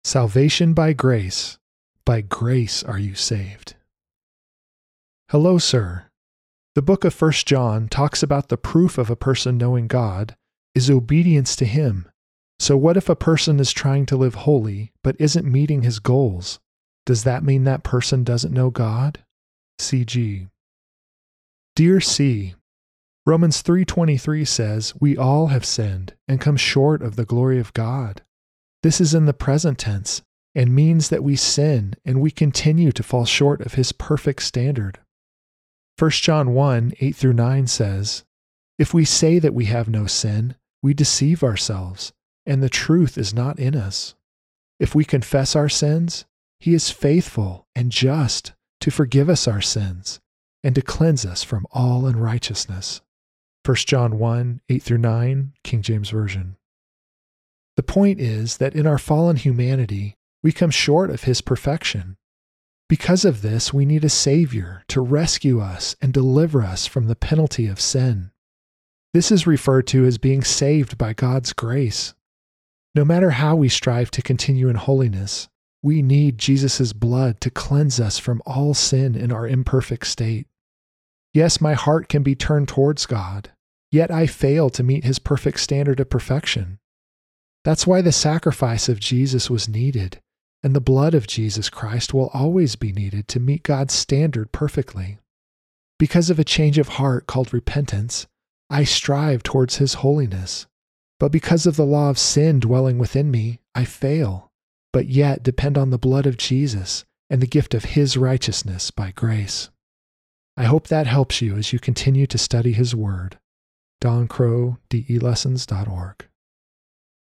Audio Lesson